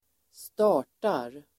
Uttal: [²st'ar_t:ar el. ²st'a:r_tar]